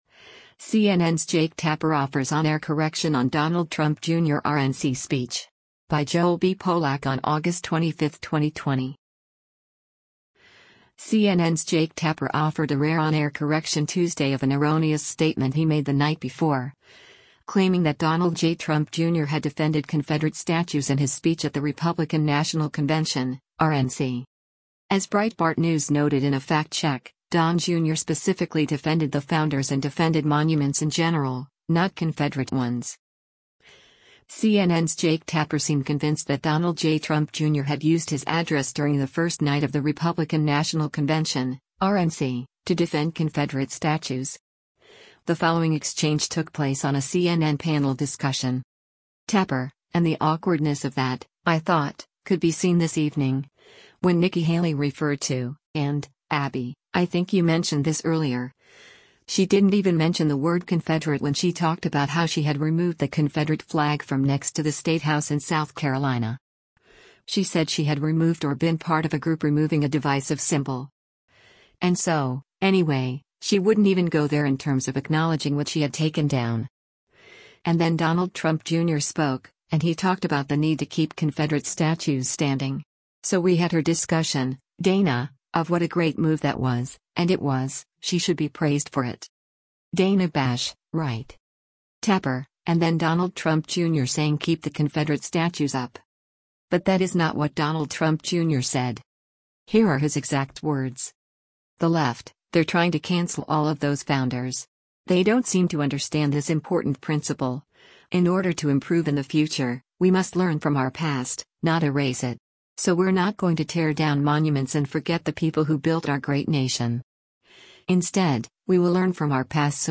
On Tuesday, Tapper corrected the record — on the air.